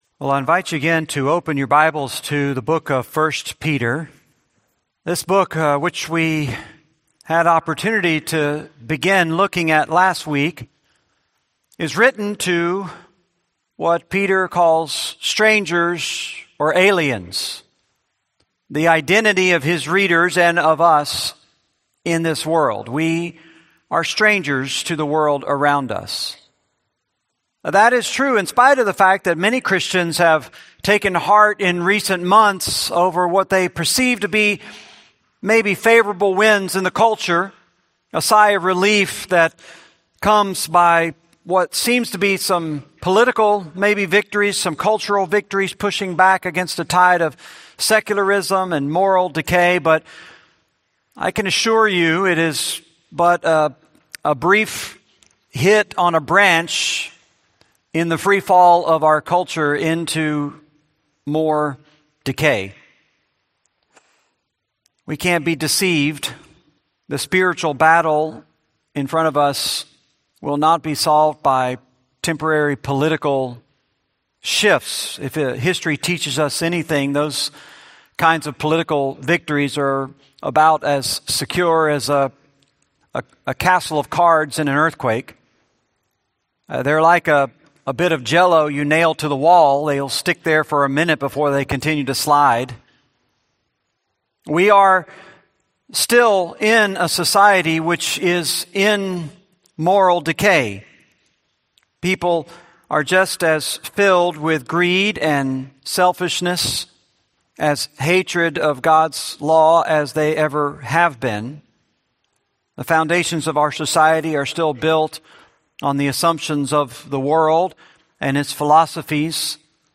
Series: 1 Peter, Sunday Sermons